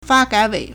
发改委 (發改委) Fā gǎi Wěi
fa1gai3wei3.mp3